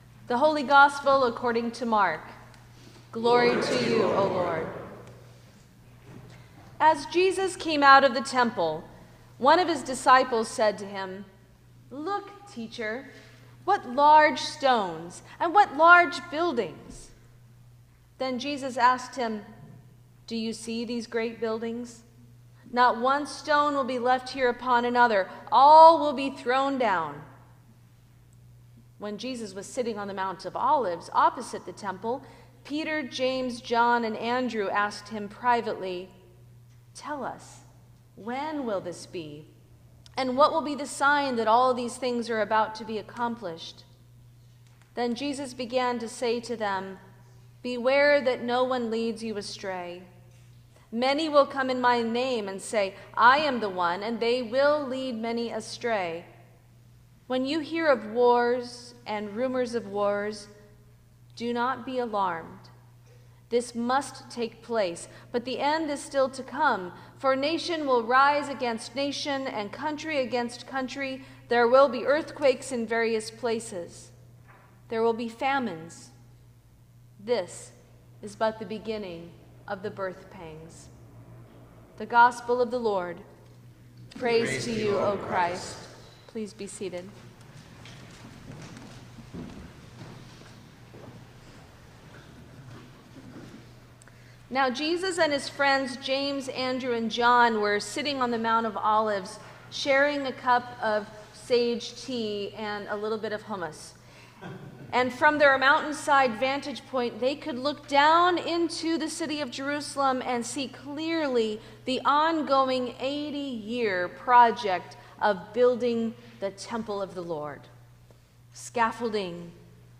Sermon for the Twenty-Sixth Sunday after Pentecost 2024